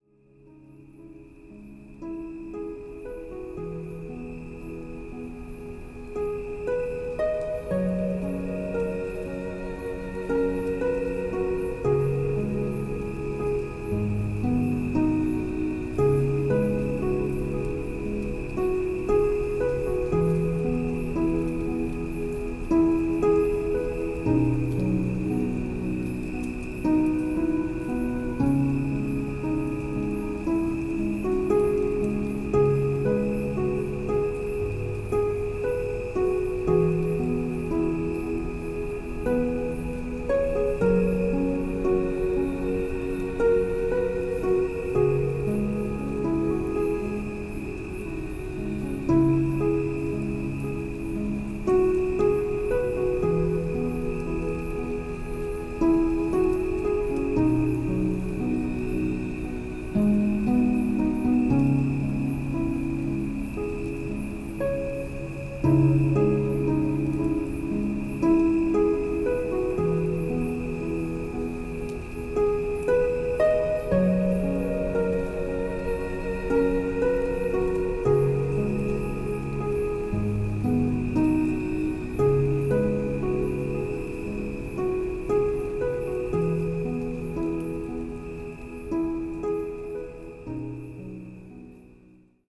44.1 kHz / Stereo Sound